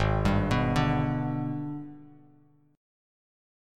AbmM7b5 chord